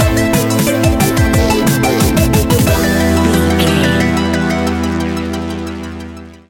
Aeolian/Minor
Fast
hypnotic
industrial
frantic
aggressive
dark
drum machine
synthesiser
sub bass
synth leads